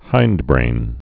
(hīndbrān)